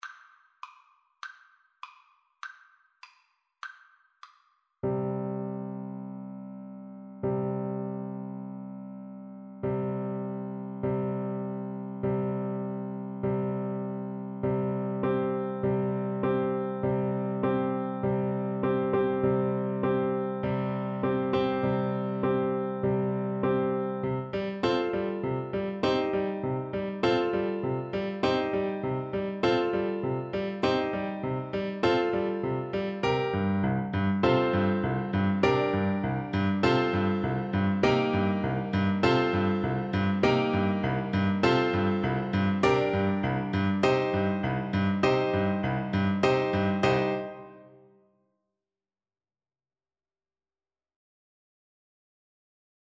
Traditional Music of unknown author.
2/4 (View more 2/4 Music)
World (View more World Clarinet Music)